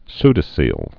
(sdə-sēl) also pseu·do·coe·lom (sdə-sēləm)